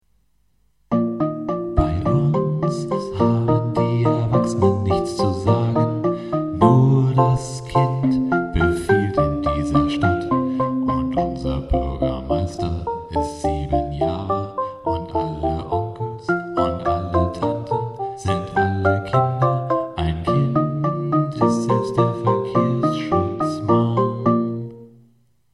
chor
voice and programming